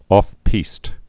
(ôfpēst, ŏf-)